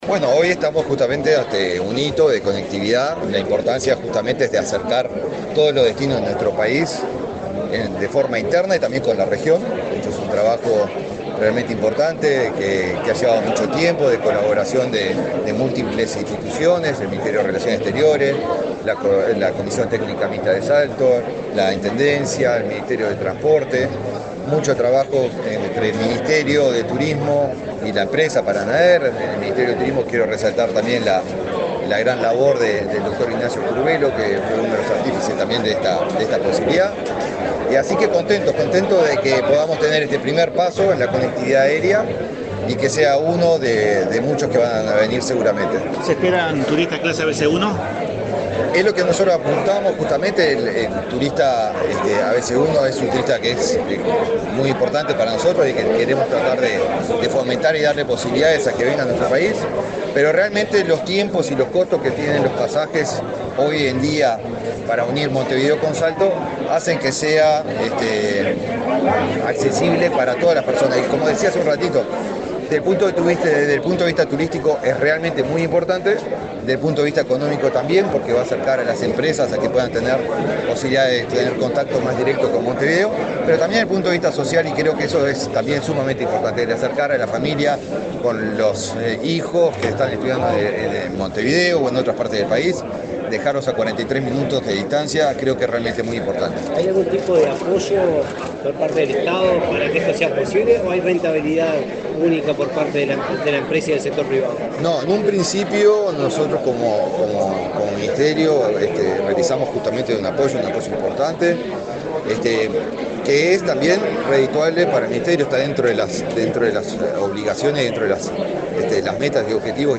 Declaraciones del ministro de Turismo, Eduardo Sanguinetti
Este martes 8, el ministro de Turismo, Eduardo Sanguinetti, dialogó con la prensa en Salto, luego de participar en el acto de recepción del vuelo